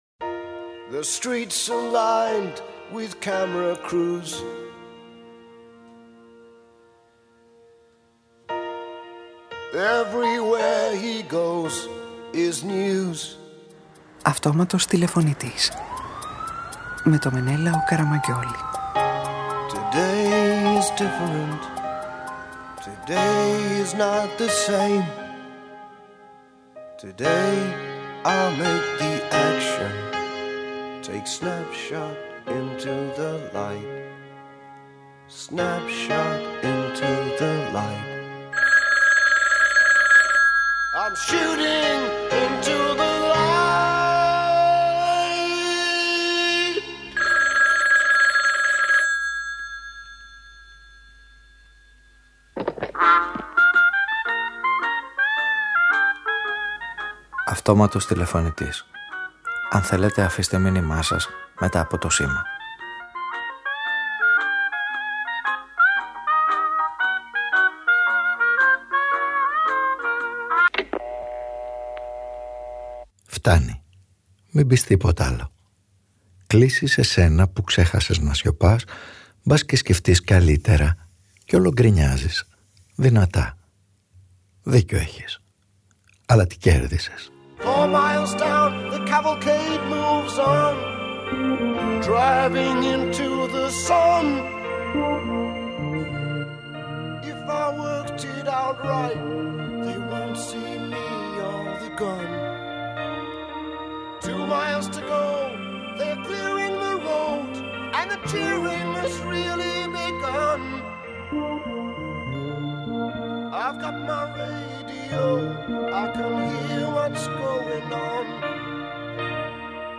Ο ήρωας αυτής της ραδιοφωνικής ταινίας νιώθει την ανάγκη να κρυφτεί ως ρίψασπις πίσω από ένα θάμνο για να μην τον βρίσκουν οι αντίπαλοι.